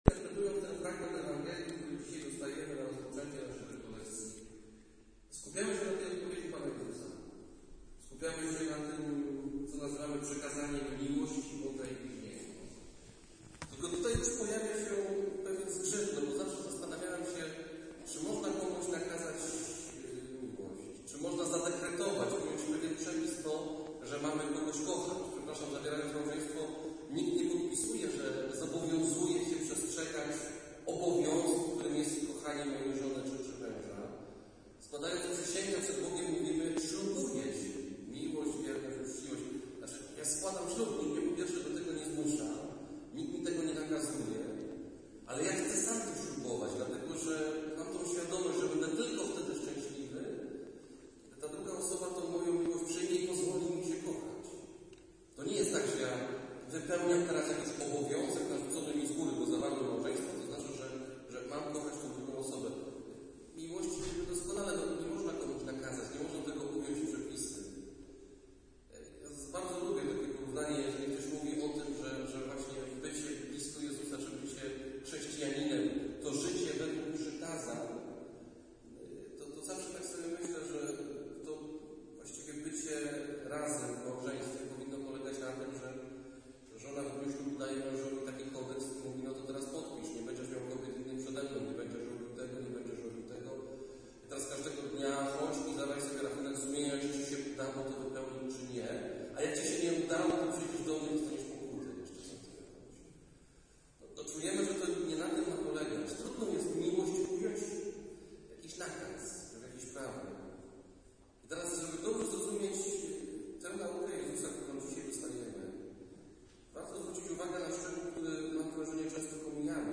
Homilia